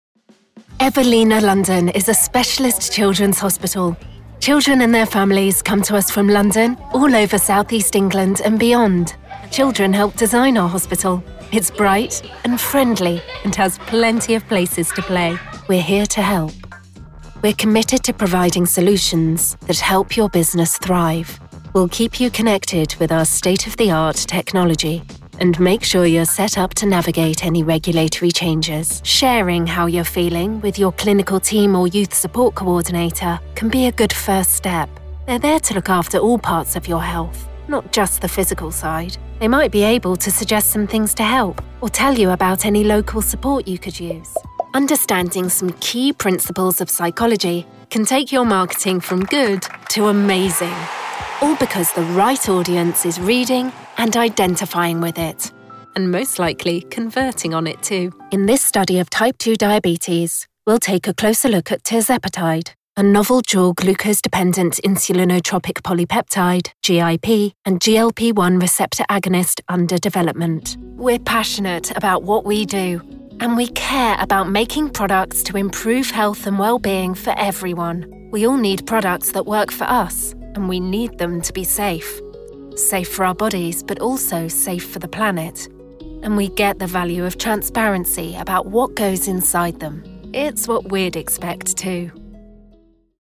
Vídeos corporativos
Con un acento inglés neutro/RP, mi voz natural es brillante, atractiva y fresca. Se la ha descrito como limpia, segura y, lo más importante, ¡versátil!
Cabina de paredes sólidas hecha a medida con tratamiento acústico completo